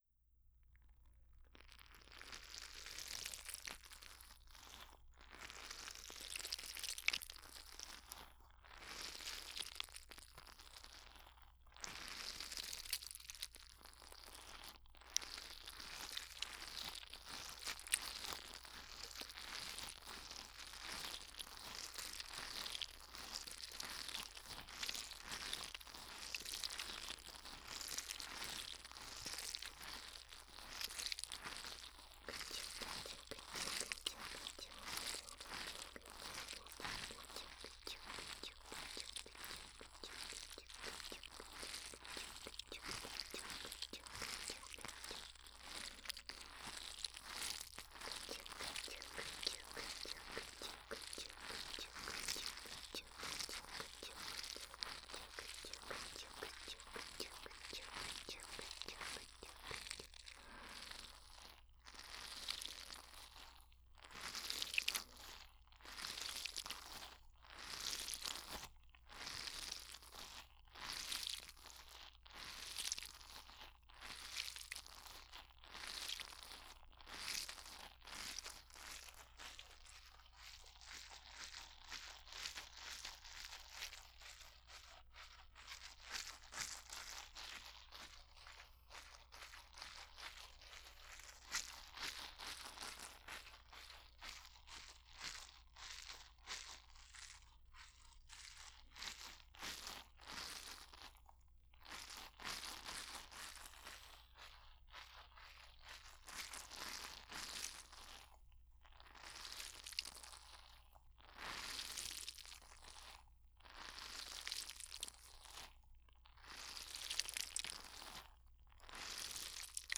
04.マッサージパートのみ.wav